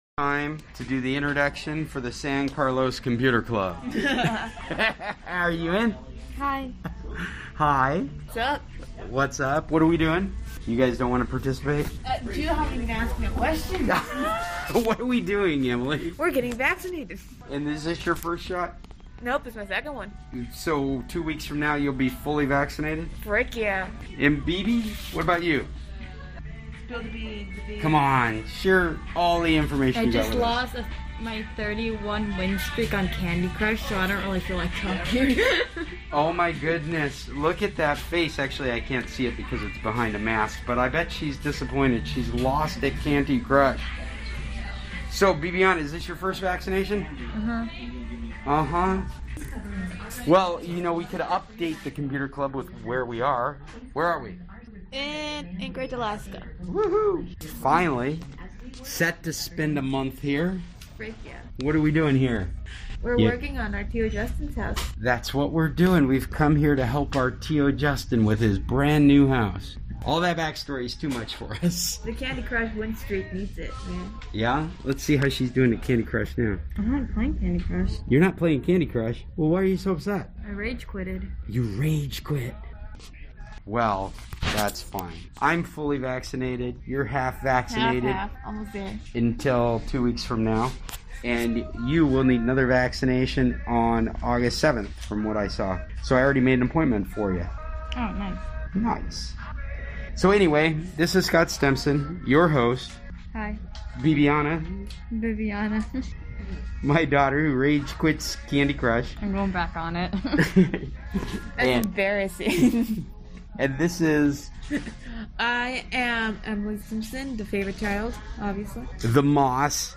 We meet every Tuesday morning online (since COVID) in a virtual meeting using Google Meet.